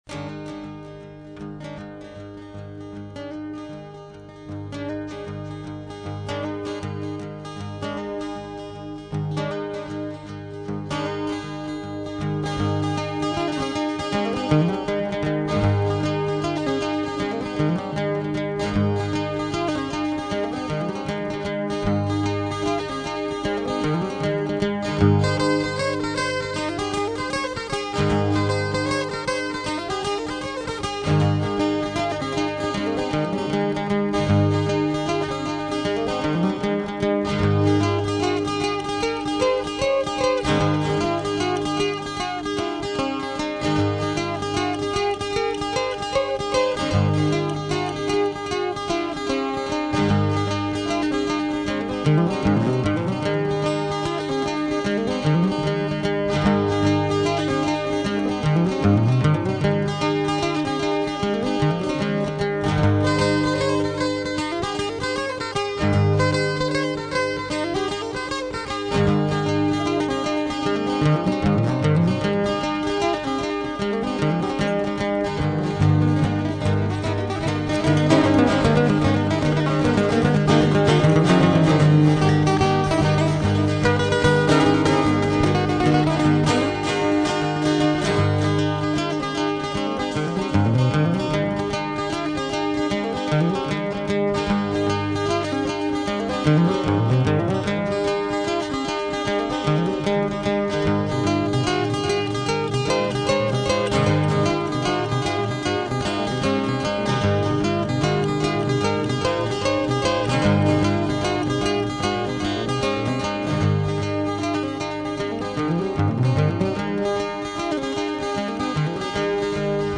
(Instrumental)
Recorded at Stoneye Studios, December 2001
Electronic Drum Pads
Saxophone